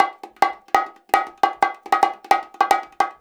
150BONGO 4.wav